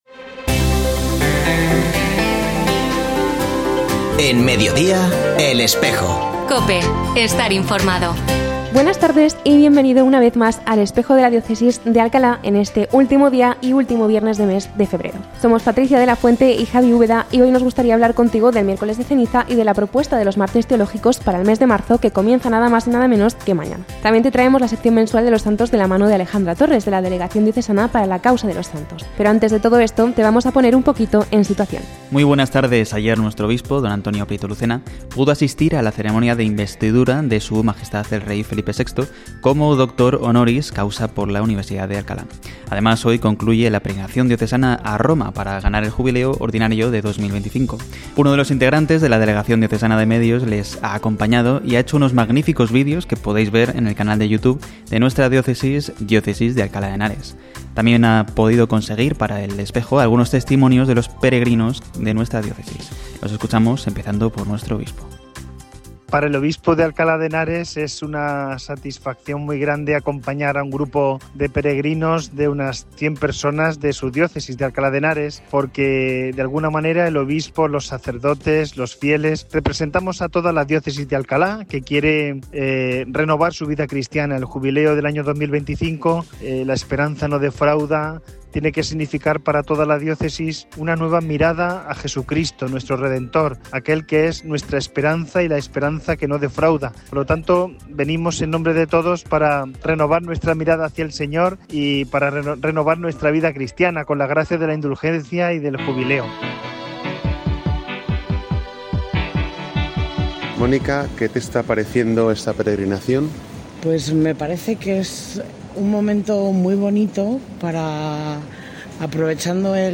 Ya se ha emitido hoy, 28 de febrero de 2025, el nuevo programa de El Espejo de la Diócesis de Alcalá en la emisora de radio COPE.
Este programa que pone punto final al mes de febrero ofrece diversos testimonios de algunos de los peregrinos que han viajado a Roma desde Venecia con la diócesis de Alcalá de Henares para ganar el Jubileo ordinario 2025.